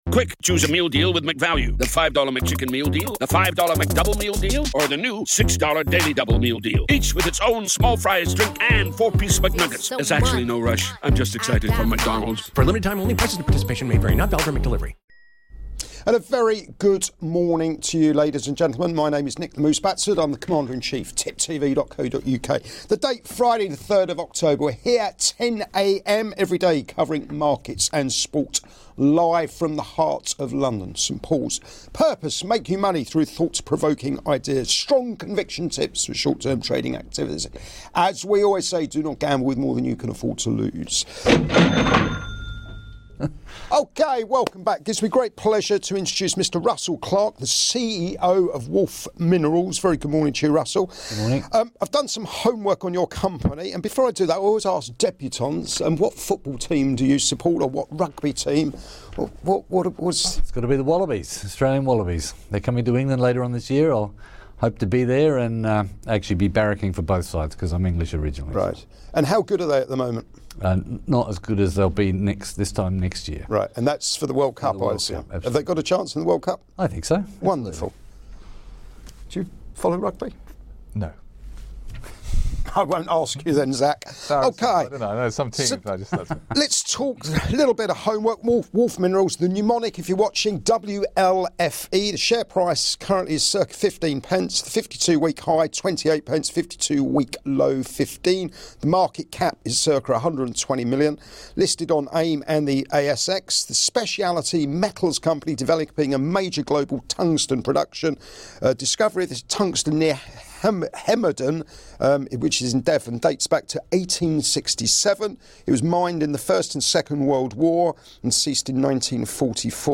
TipTV Business / TipTV CEO Interview